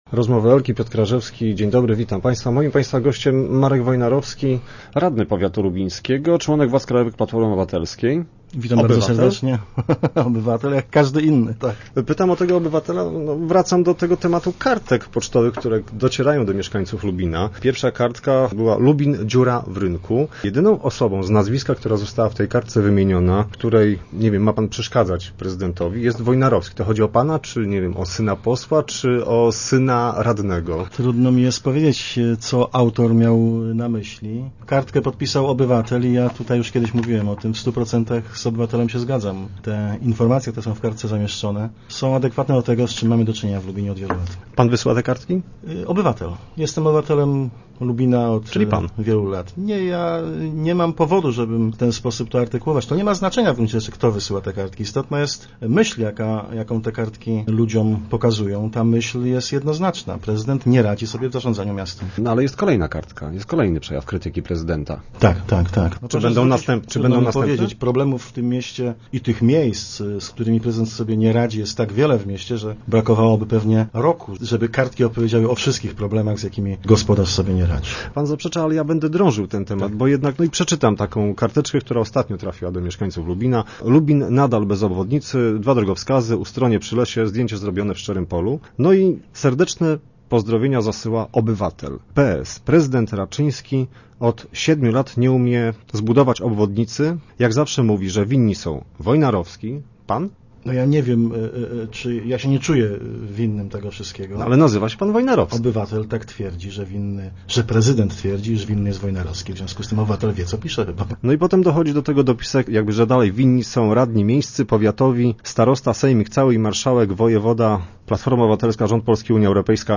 Co ma z nim wspólnego Marek Wojnarowski, szef radnych PO w powiecie? Dzisiaj był gościem Rozmów Elki.